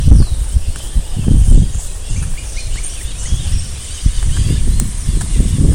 Campo Flicker (Colaptes campestris)
Location or protected area: Villa Rosa
Condition: Wild
Certainty: Recorded vocal
carpintero-campestre.mp3